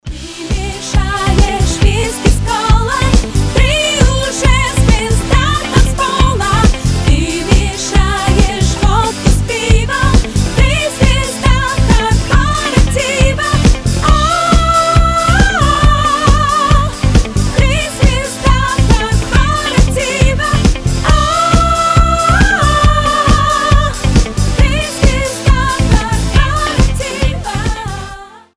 • Качество: 320, Stereo
веселые
смешные